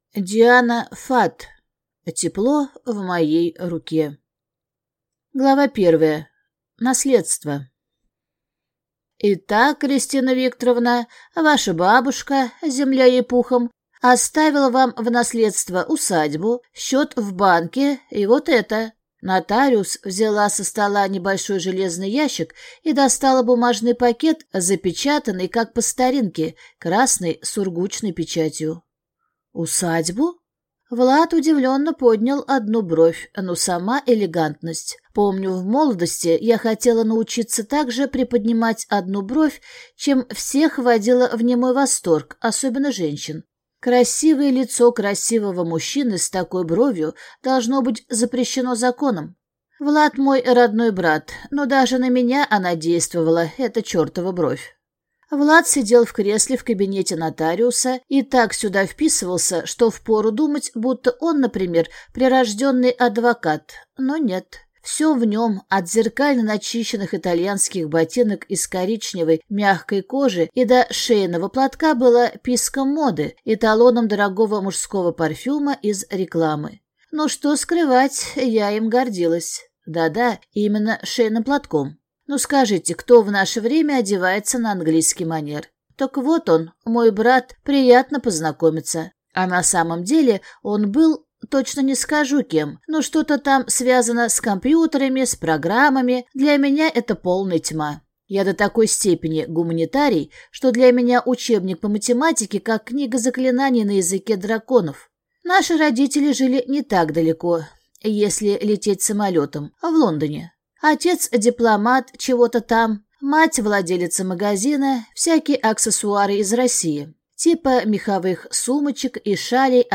Аудиокнига Тепло в моей руке | Библиотека аудиокниг
Прослушать и бесплатно скачать фрагмент аудиокниги